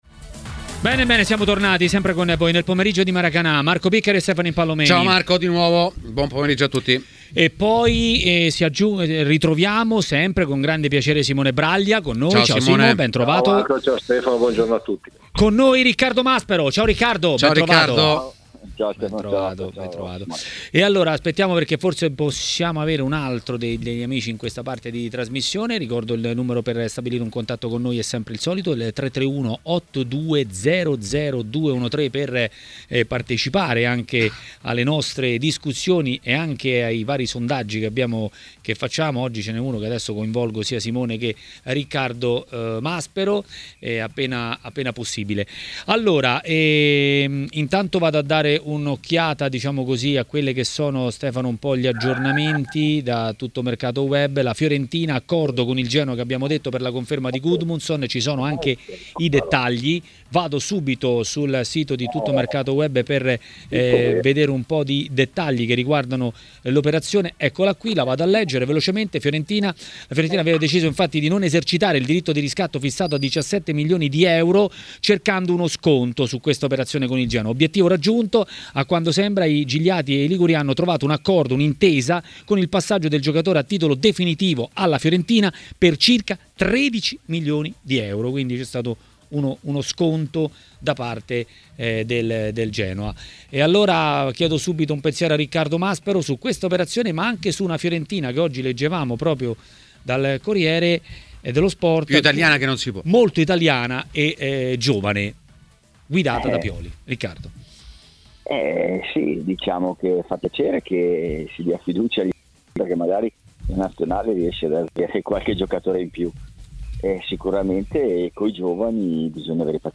Ospite di Maracanà, trasmissione di TMW Radio, è stato Riccardo Maspero, ex attaccante.